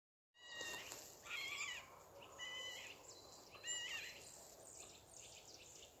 Birds -> Crows ->
Eurasian Jay, Garrulus glandarius
StatusVoice, calls heard